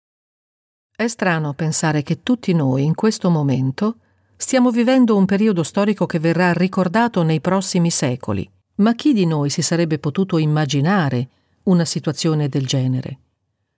I am attaching a file I recorded on Logic and then exported on Audacity: here the peak and the RMS are ok but it says that the noise floor is dead!
My Italian isn’t what it should be, but in places it does sound like the start of words have been cut-off,
as occurs with an improperly set noise-gate.